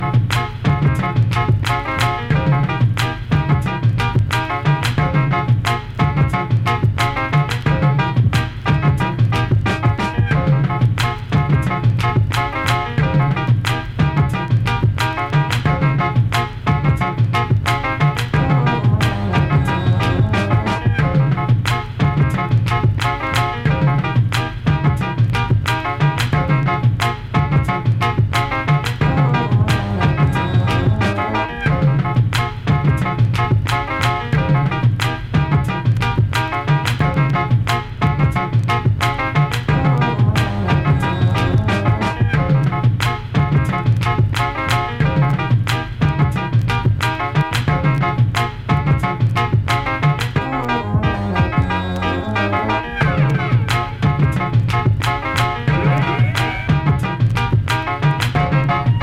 コラージュ的サンプリングのブレイクビーツ
ピアノのサンプリングが耳に残りまくる
両曲に共通しているのはサイケデリック！